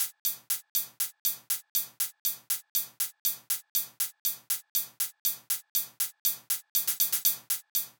23 Hihats.wav